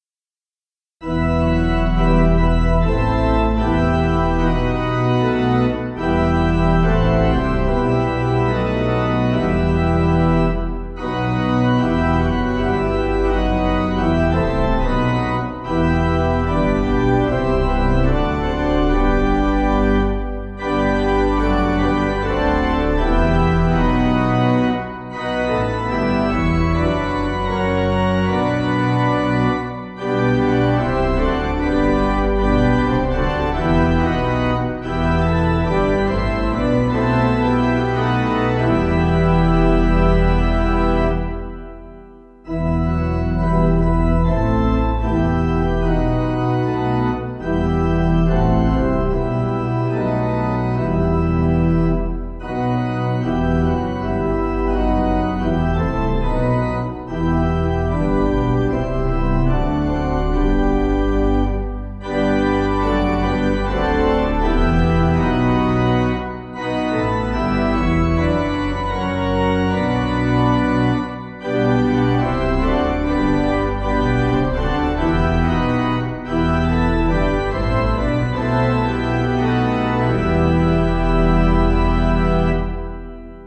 Organ
(CM)   4/Em